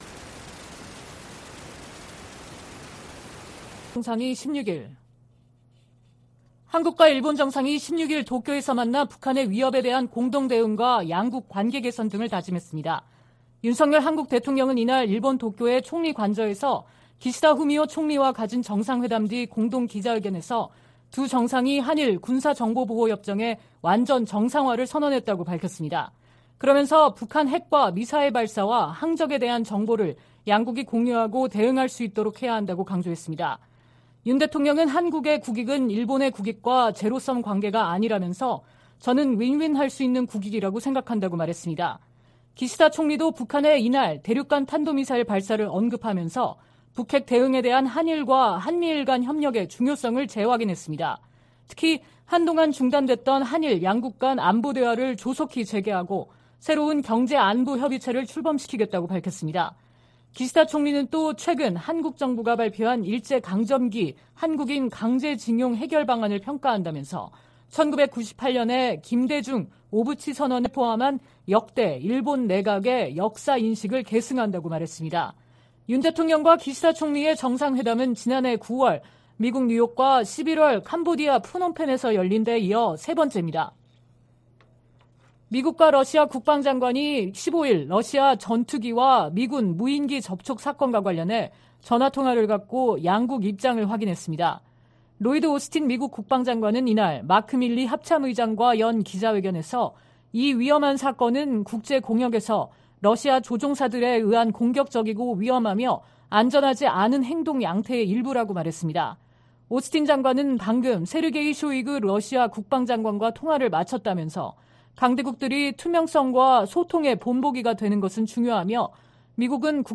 VOA 한국어 '출발 뉴스 쇼', 2023년 3월 17일 방송입니다. 윤석열 한국 대통령과 기시다 후미오 일본 총리가 정상회담을 통해 북핵과 미사일 위협에 대응한 공조를 강화하기로 했습니다. 북한이 16일 ‘화성-17형’으로 추정되는 대륙간탄도미사일(ICBM)을 발사했습니다. 미국 정부는 북한의 ICBM 발사가 안보리 결의에 위배되고 역내 긴장을 고조시킨다며 강력 규탄했습니다.